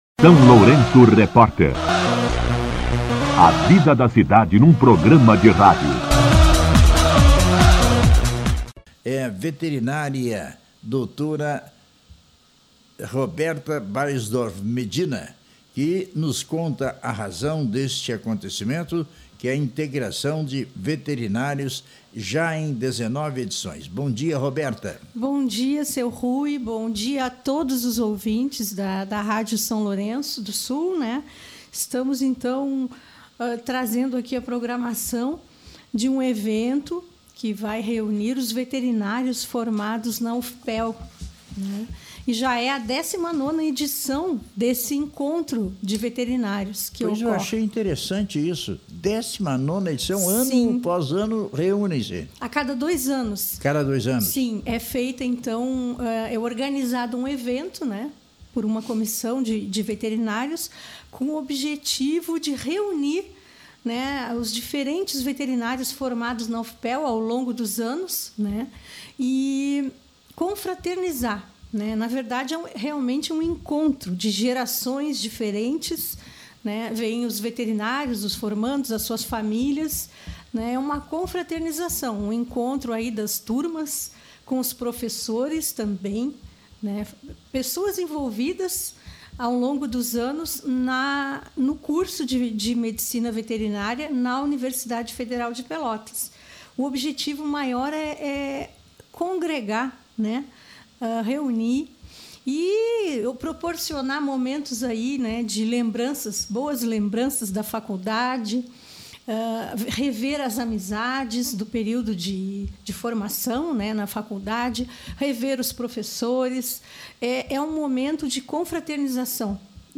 esteve no SLR RÁDIO para falar sobre o XIX EVETPEL, o Encontro de Veterinários da UFPel, que será realizado em São Lourenço do Sul.